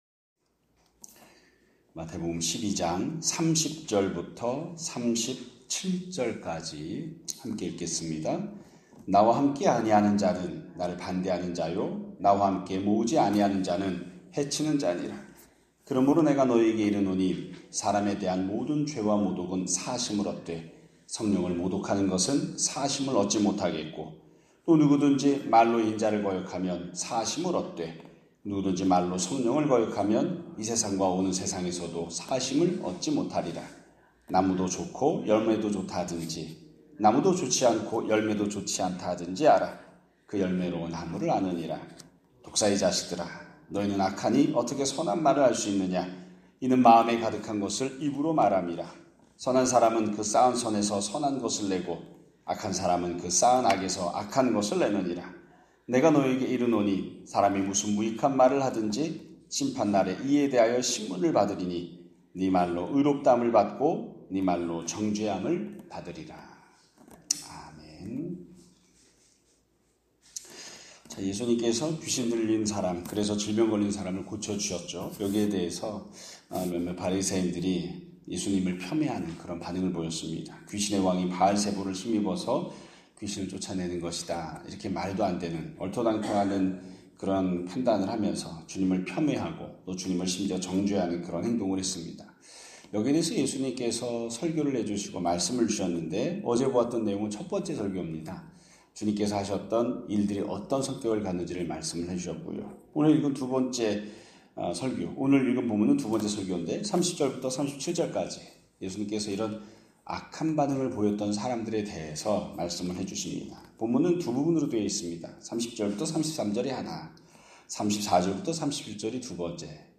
2025년 9월 16일 (화요일) <아침예배> 설교입니다.